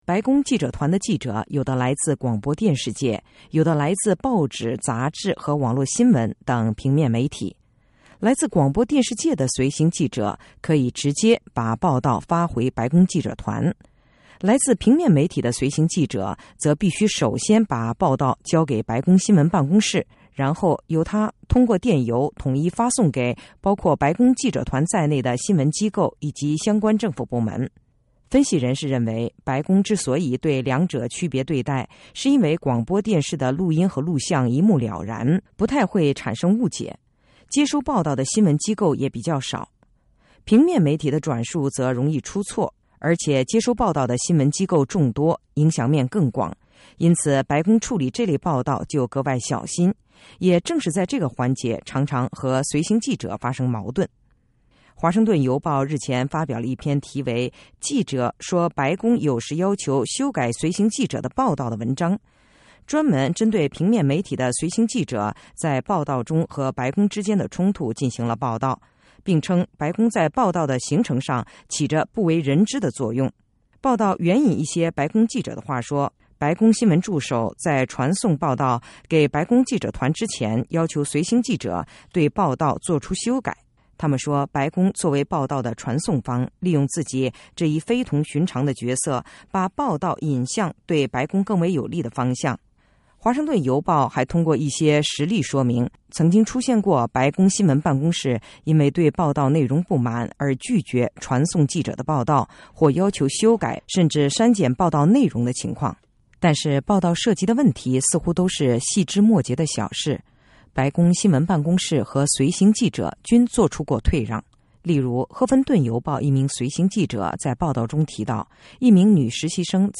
针对上述指称，美国之音法律窗口采访了美国新闻机构的一些随行记者。